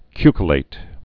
(kykə-lāt, ky-kŭlāt)